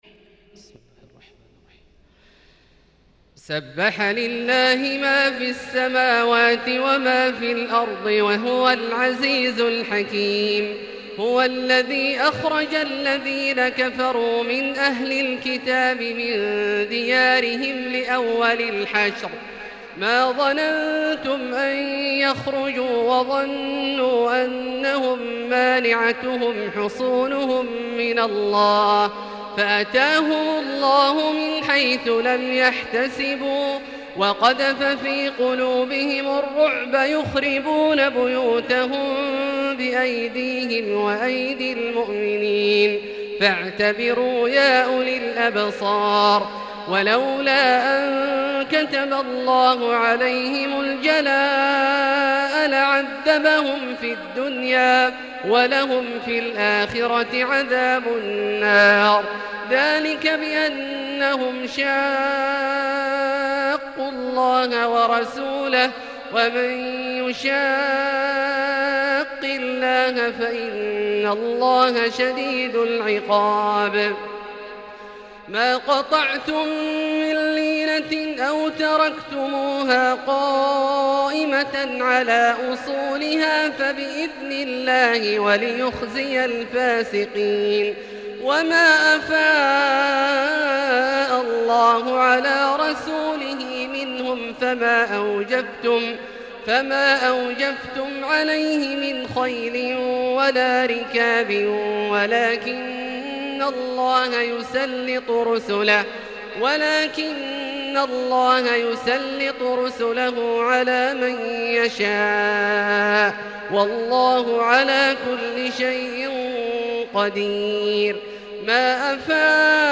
تهجد ليلة 28 رمضان 1441هـ | من سورة الحشر إلى سورة الطلاق | tahajud prayer The 28th night of Ramadan 1441H | > تراويح الحرم المكي عام 1441 🕋 > التراويح - تلاوات الحرمين